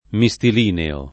mistilineo [ mi S til & neo ] agg.